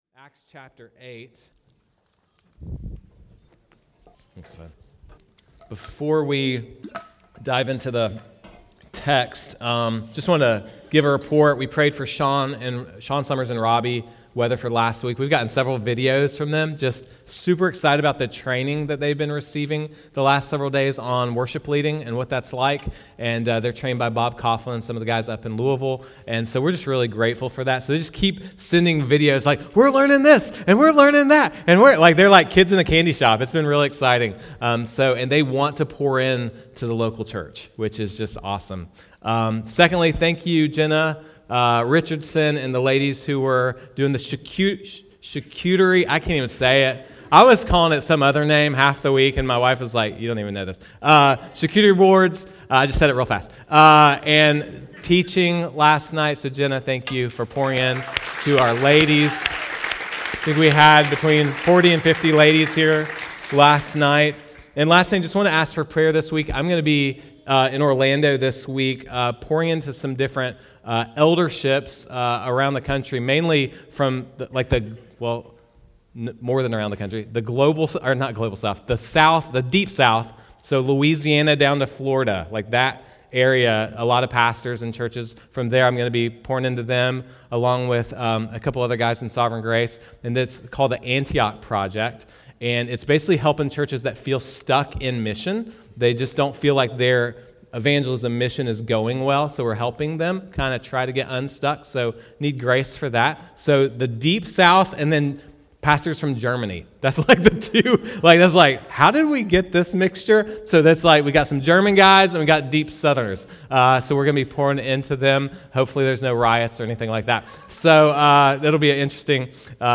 From Series: "Non-Series Sermons"
These sermons have been preached outside a normal sermon series at Risen Hope.